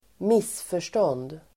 Uttal: [²m'is:för_stån:d]